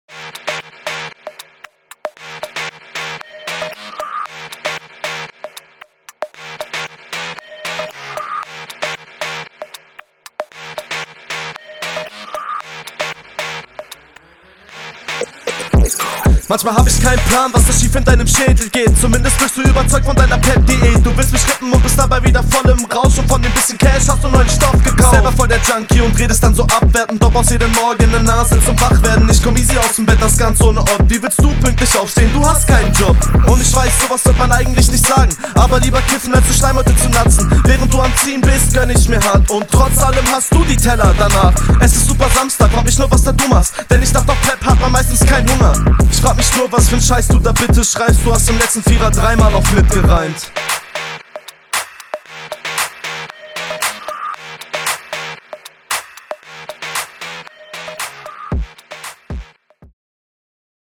Klang ist auch bei dir gut und verständlich.